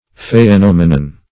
phaenomenon - definition of phaenomenon - synonyms, pronunciation, spelling from Free Dictionary Search Result for " phaenomenon" : The Collaborative International Dictionary of English v.0.48: Phaenomenon \Ph[ae]*nom"e*non\, n. [L.]
phaenomenon.mp3